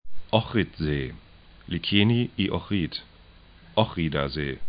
Pronunciation
'ɔxrɪt-ze:
'ɔxridaze: